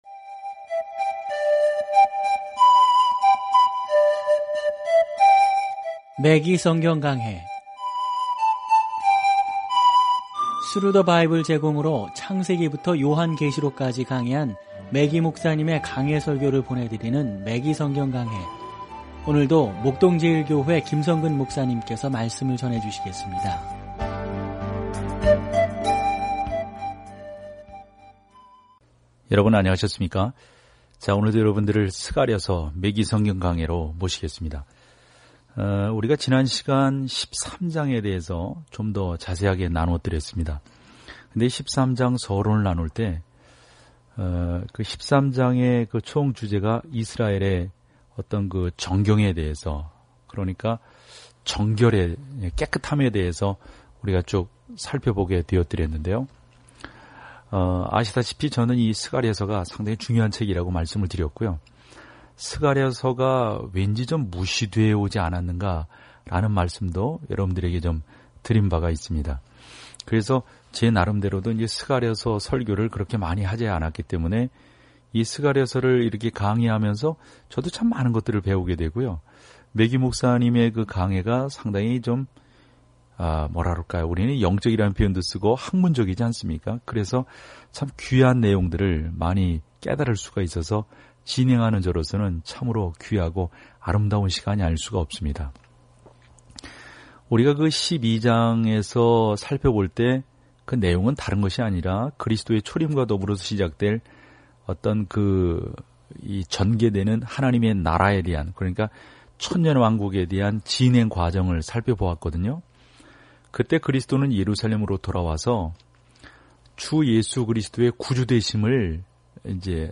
말씀 스가랴 13:1-3 28 묵상 계획 시작 30 묵상 소개 선지자 스가랴는 사람들에게 미래의 희망을 주겠다는 하나님의 약속에 대한 환상을 공유하고 그들에게 하나님께 돌아올 것을 촉구합니다. 오디오 공부를 듣고 하나님의 말씀에서 선택한 구절을 읽으면서 매일 스가랴를 여행하세요.